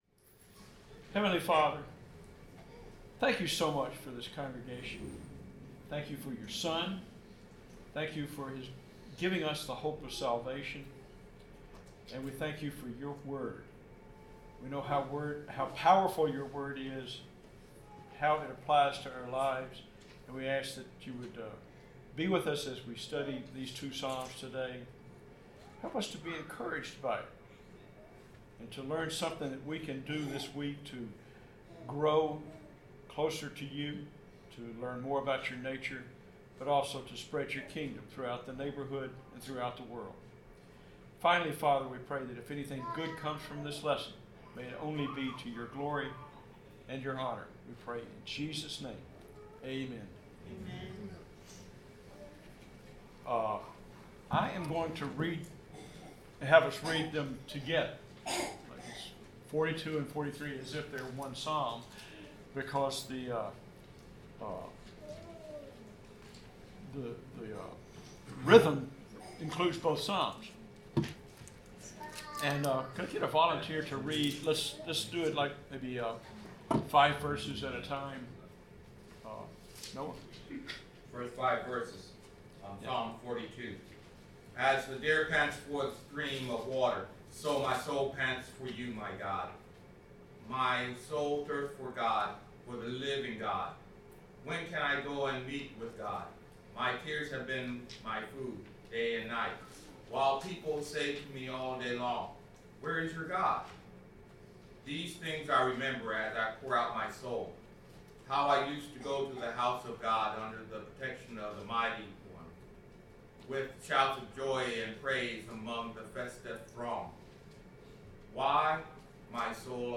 Bible class: Psalms 42-43
Service Type: Bible Class